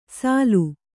♪ sālu